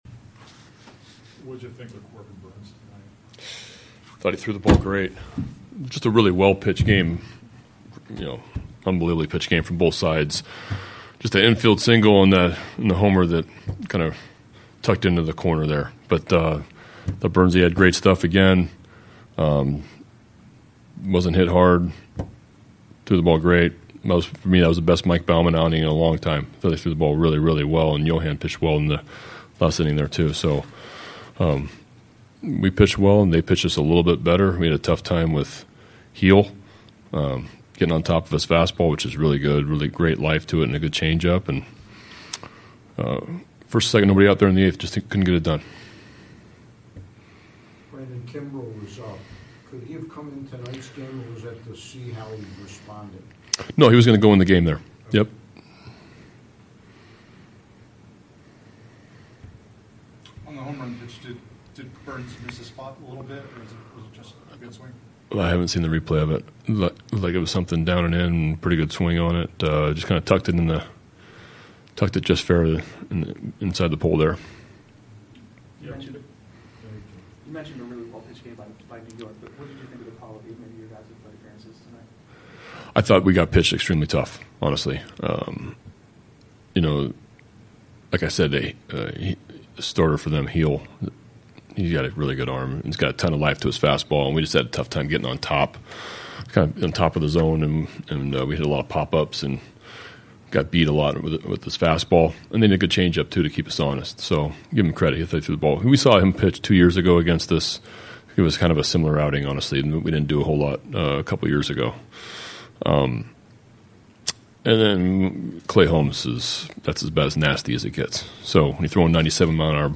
Brandon Hyde meets with media following 2-0 loss to Yankees on Wednesday
Locker Room Sound